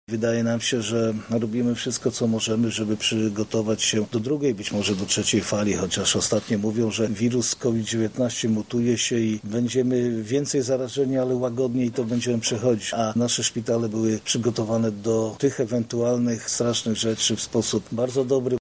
Jest to dodatkowy element walki z Covid-19– mówi marszałek województwa lubelskiego Jarosław Stawiarski